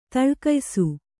♪ taḷkaysu